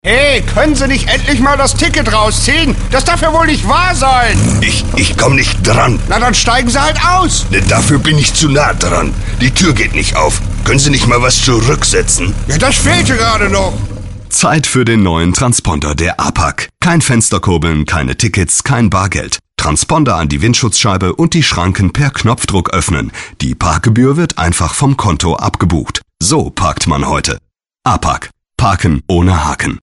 deutscher Sprecher und Sänger mit variantenreicher Stimme.
Sprechprobe: Industrie (Muttersprache):